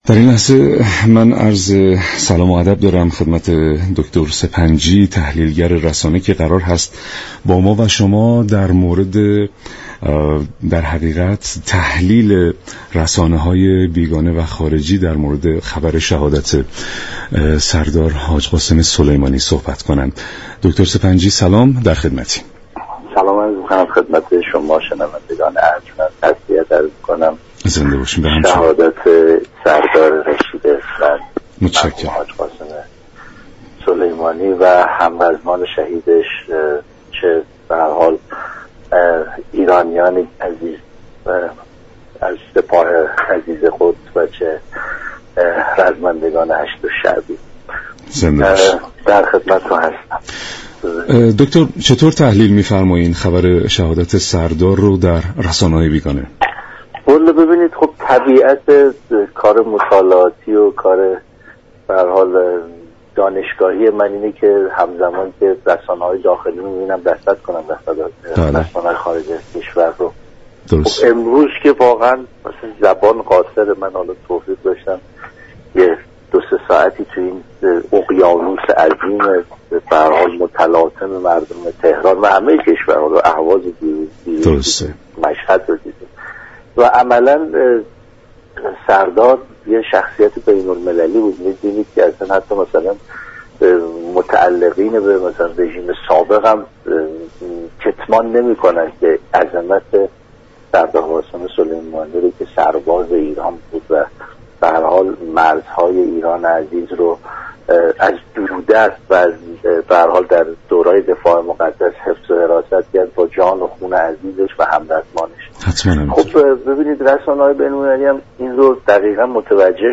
گفت و گو با رادیو ایران